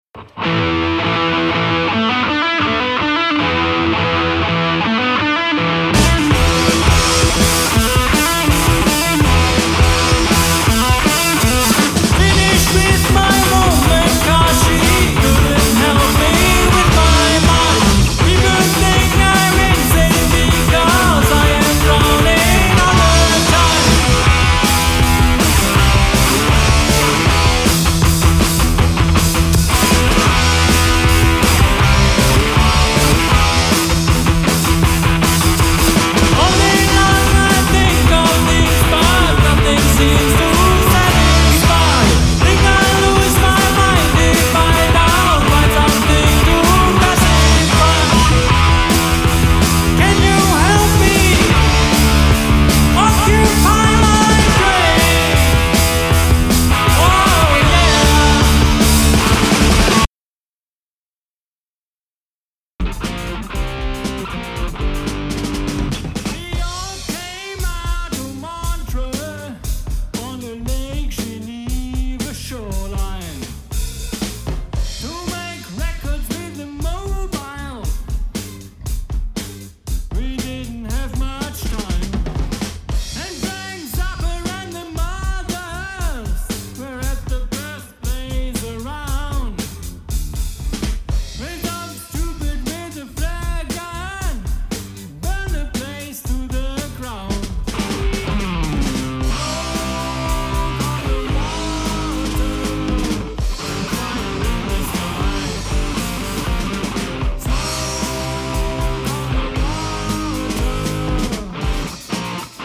ich singe und spiele gitarre.
deshalb nur 3 instrumente